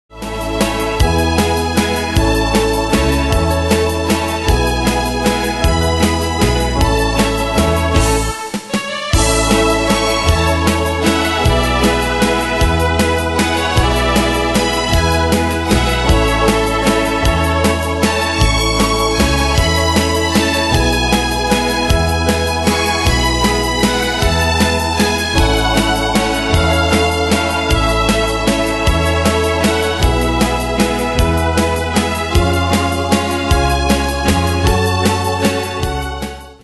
Style: Retro Année/Year: 1948 Tempo: 155 Durée/Time: 3.24
Danse/Dance: Valse/Waltz Cat Id.
Pro Backing Tracks